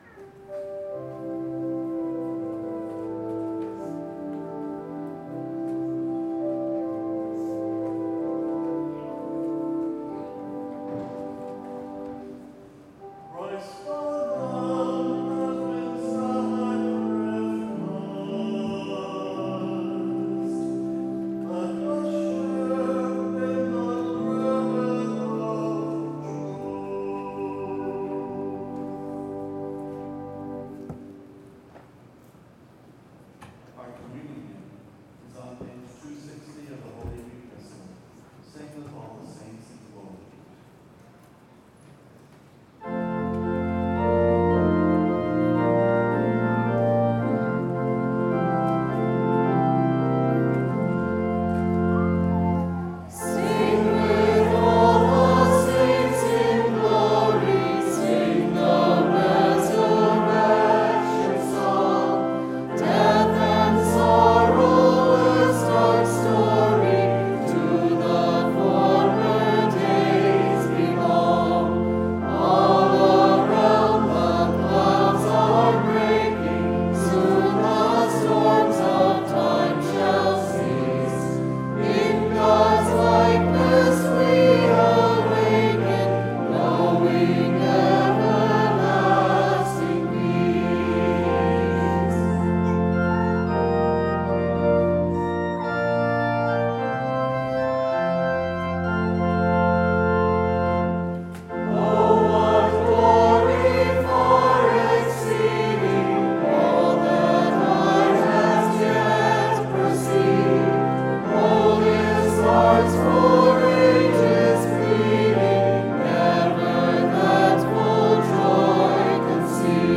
April 4, 2026 - Easter Vigil Mass
St. Catharine Choir